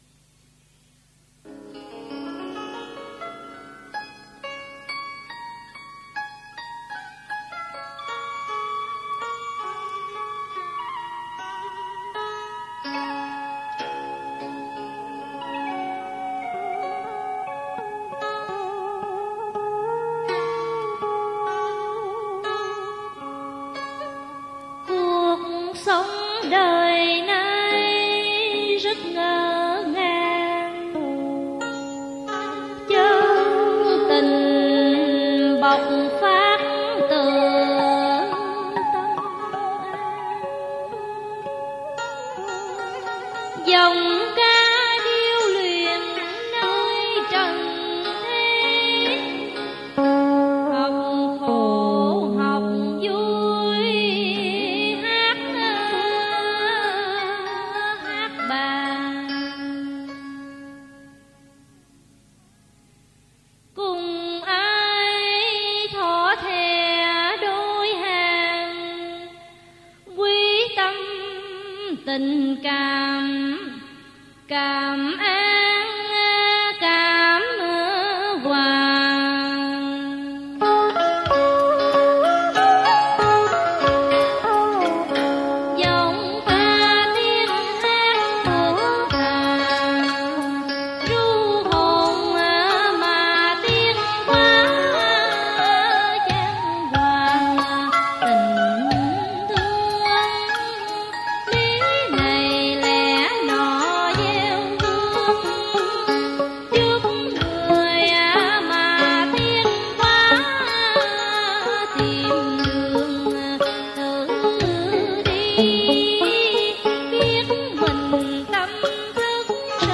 Dân Ca & Cải Lương
theo điệu nói thơ Bặc Liêu